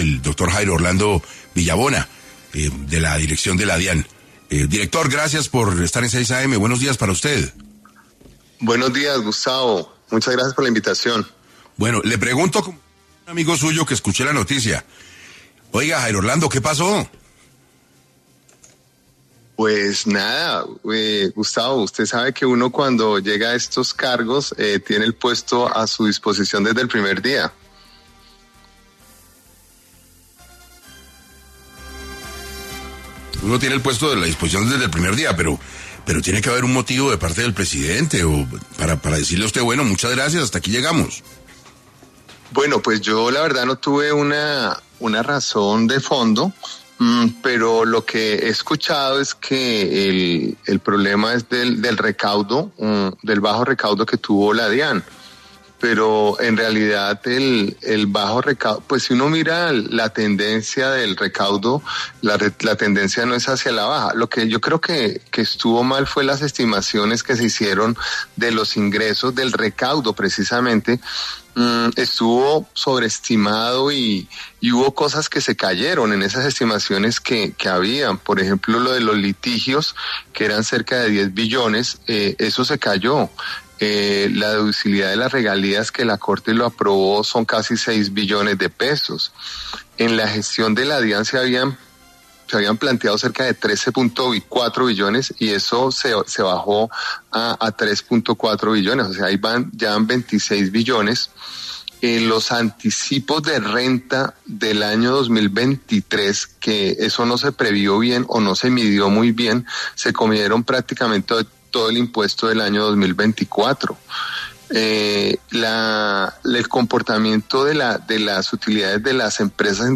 En 6AM de Caracol Radio estuvo Jairo Orlando Villabona, director saliente de la DIAN, para hablar sobre por qué el presidente Gustavo Petro le pidió su renuncia en la dirección de la entidad.